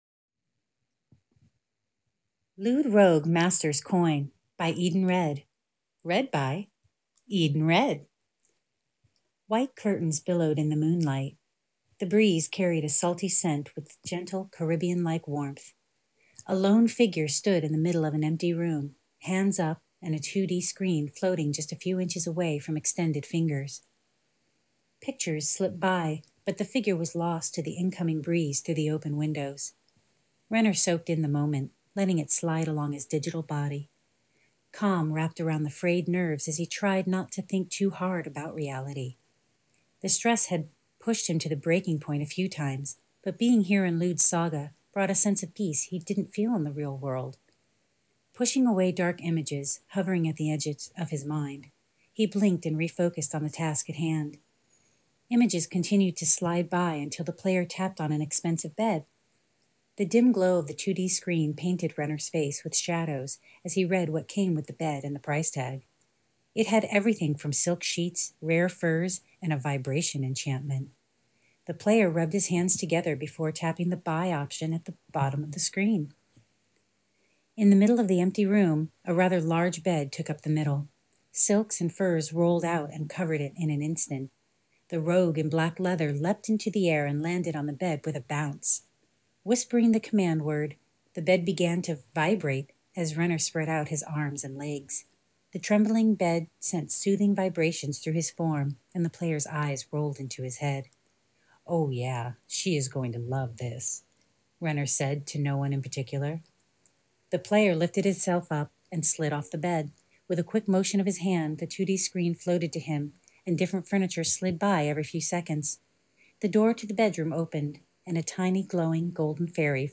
I had a lot of fun putting this together but I must warn that this is not a professional recording. Fighting a cold, my voice came off a little smokey but I enjoyed putting myself out there.